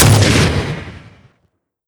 sfx_shotgun_fire_1.wav